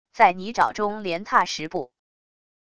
在泥沼中连踏十步wav音频